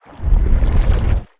Sfx_creature_spikeytrap_unbury_01.ogg